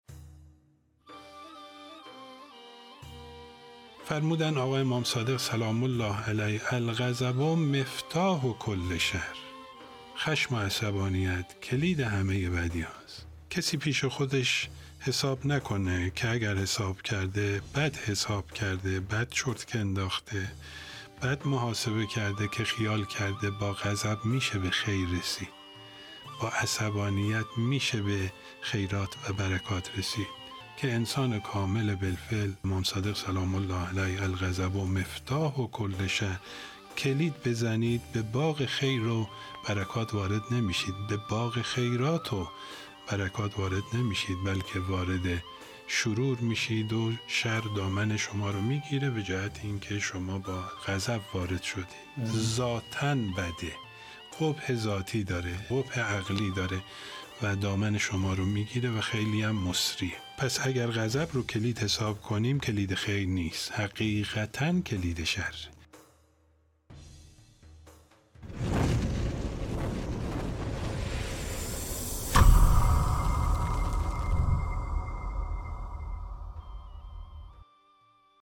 درس اخلاق | چرا عصبانیت باعث دوری از خیرات و برکات می‌شود؟